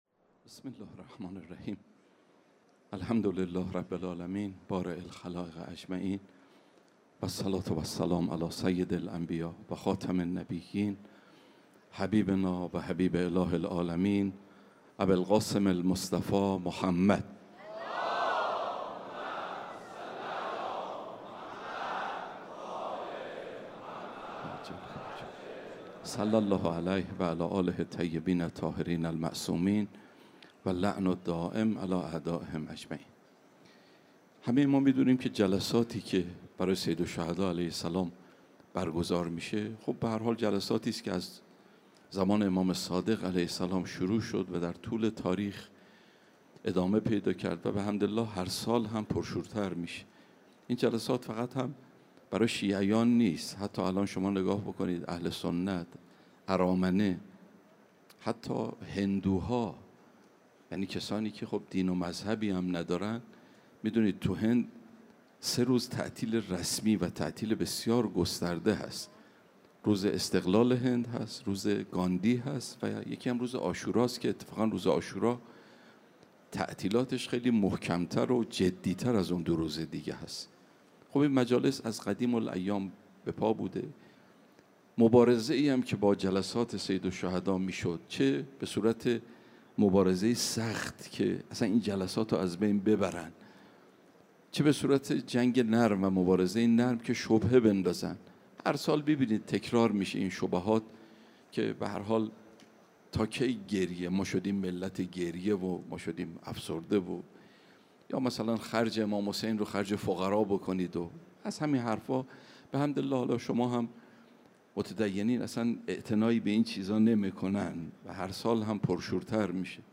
سخنرانی دهه اول محرم 1402